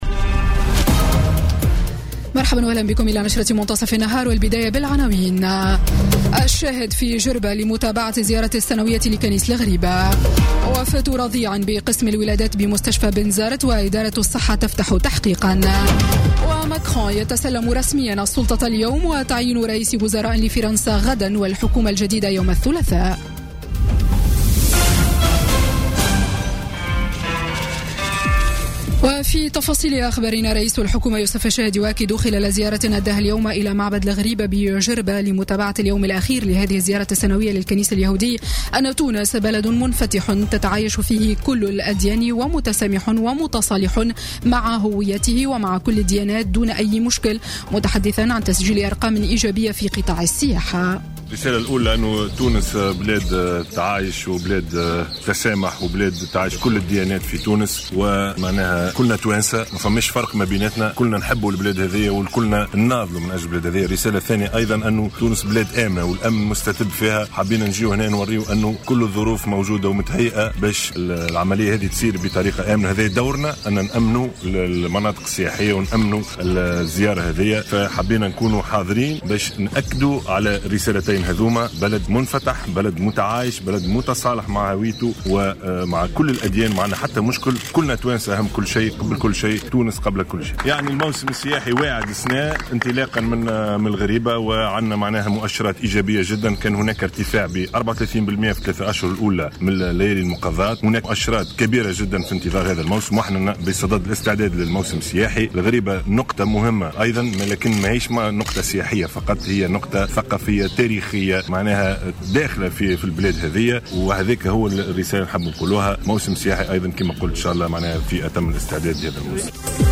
نشرة أخبار منتصف النهار ليوم الأحد 14 ماي 2017